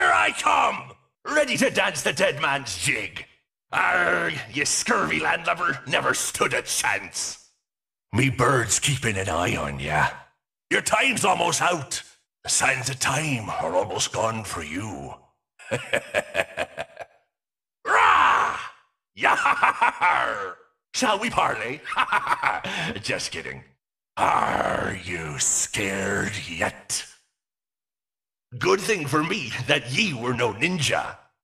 Fnaf AR Foxy Voice 2 sound effects free download